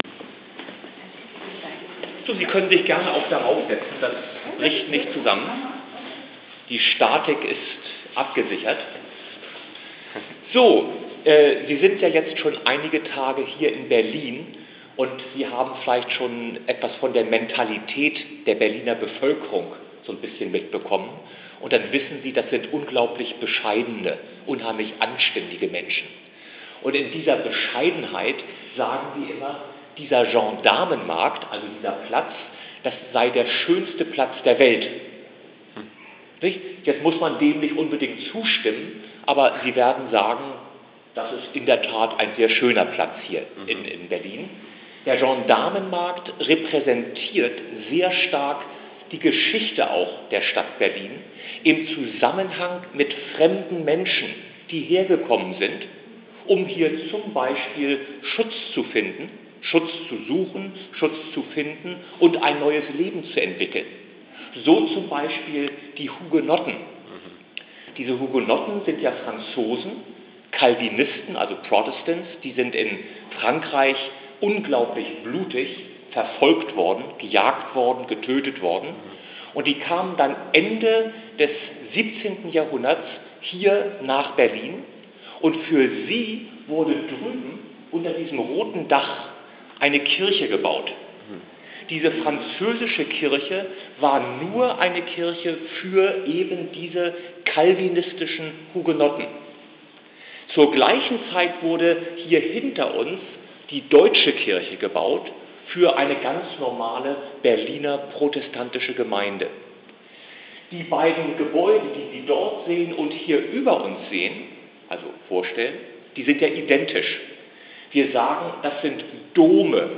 Der Führer erklärt, warum es zwei Dome gibt. / The guide explains why there are two cathedrals.